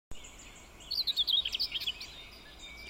Птицы -> Славковые ->
серая славка, Curruca communis
СтатусПоёт